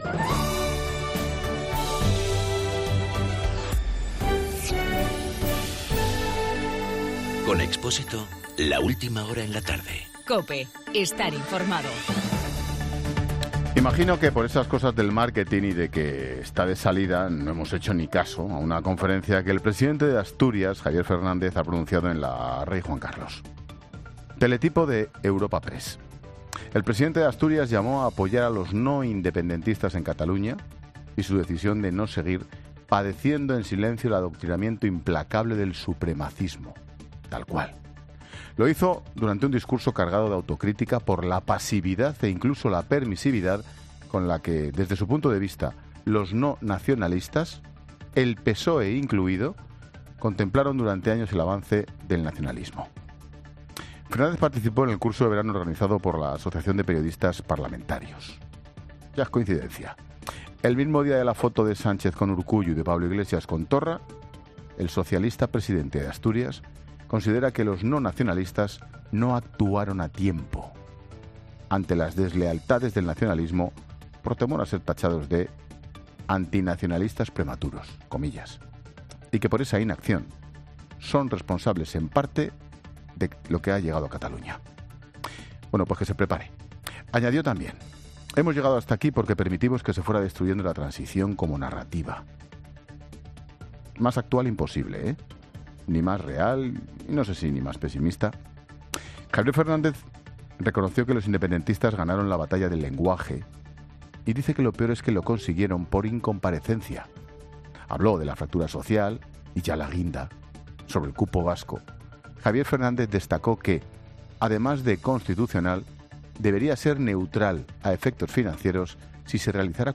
Monólogo de Expósito
El comentario de Ángel Expósito sobre la declaración del presidente de Asturias, Javier Fernández, contra los independentistas.